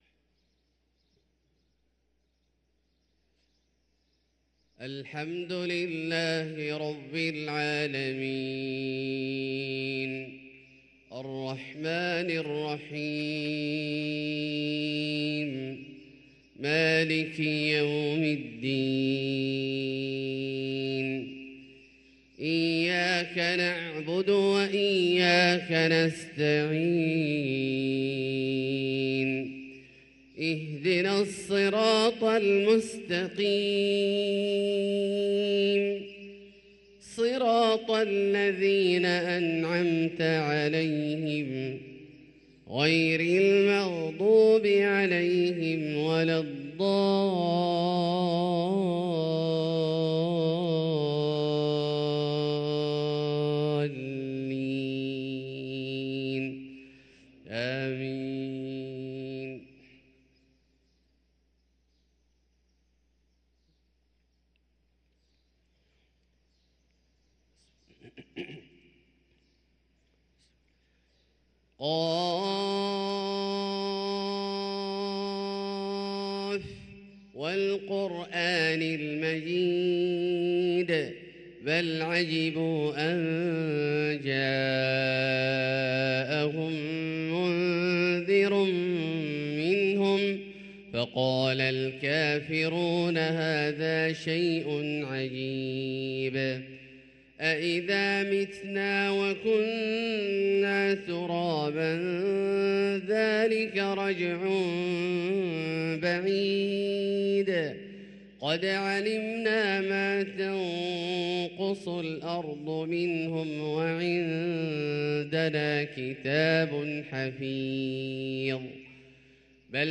صلاة الفجر للقارئ عبدالله الجهني 10 رجب 1444 هـ